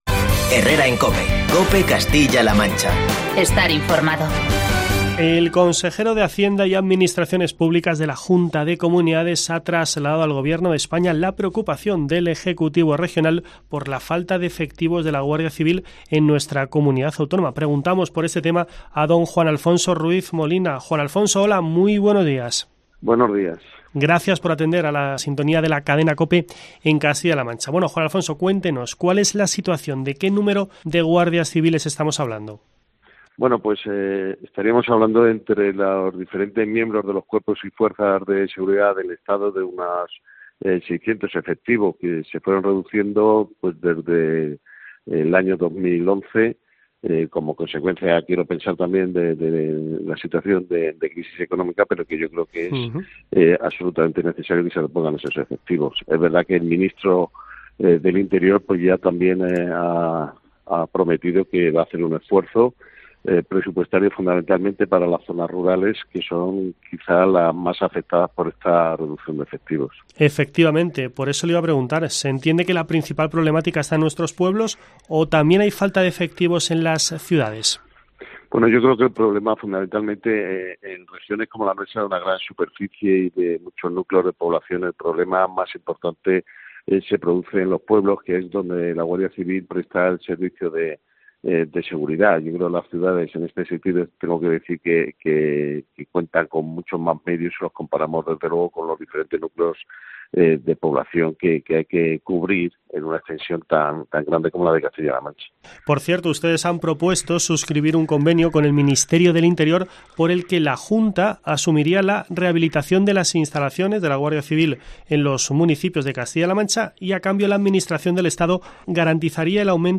Escucha la entrevista con el consejero de Hacienda y Administraciones Públicas, Juan Alfonso Ruiz Molina